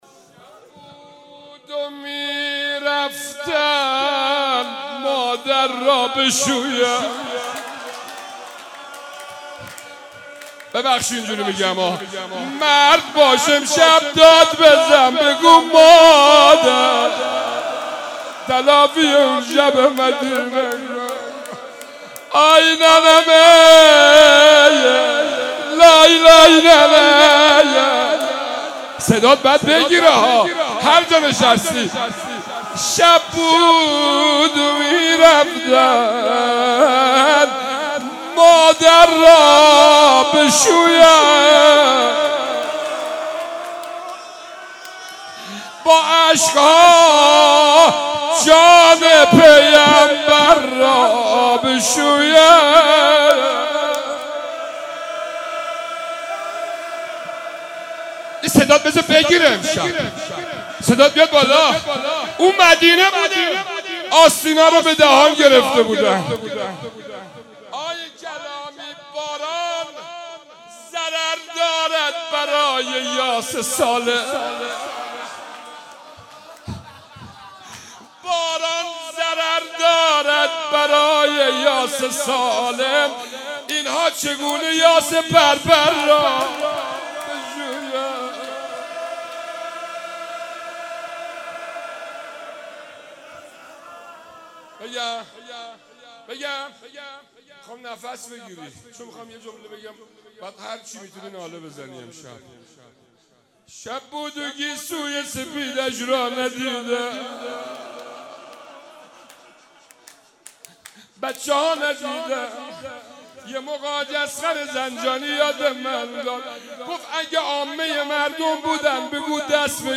هیئت انصار العباس(ع)/مراسم هفتگی
روضه حضرت زهرا(س)